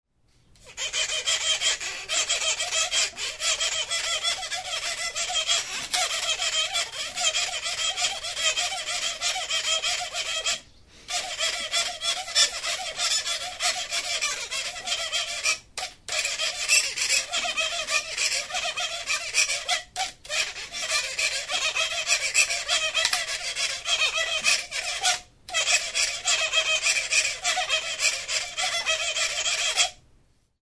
Cordes -> Frottées
Enregistré avec cet instrument de musique.
Arto-makila batekin egindako 'biolina' edo hots-jostailua da. Biolinak eta arkuak 3 soka dituzte.